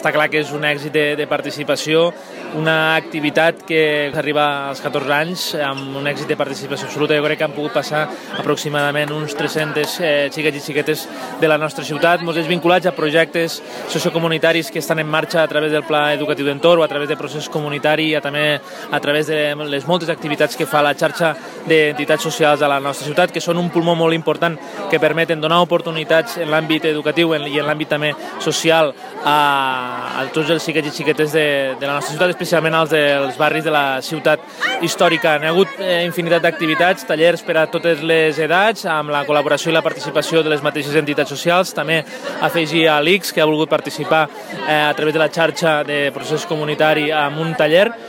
Víctor Grau, tinent d’alcaldia responsable d’acció comunitària ha remarcat que l’Artxibarri és